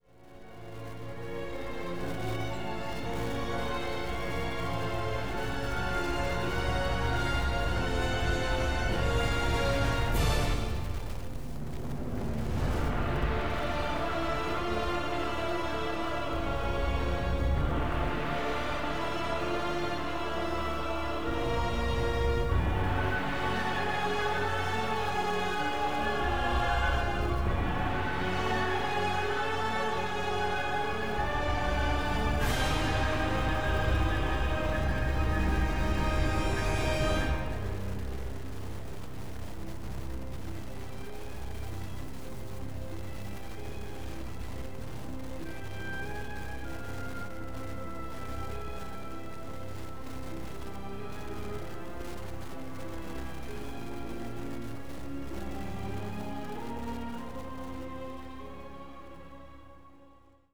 • Musica
Original track music